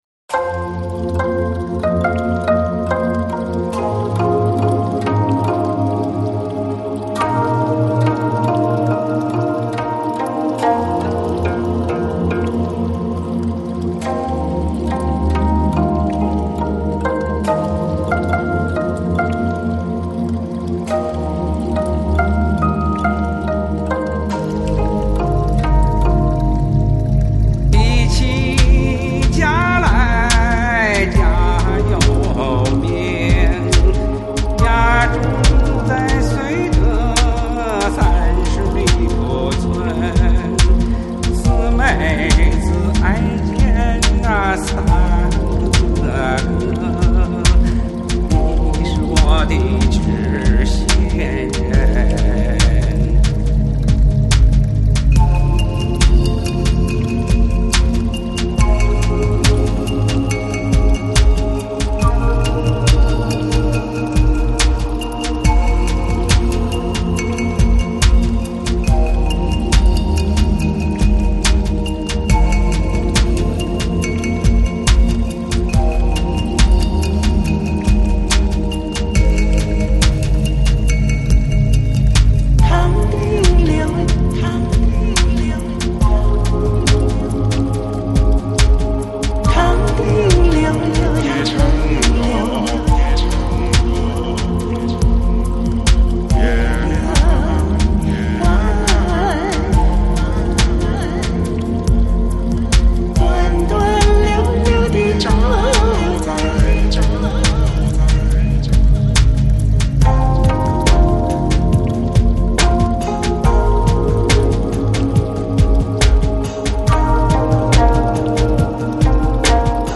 Жанр: Electronic, Lounge, Chill Out, Downtempo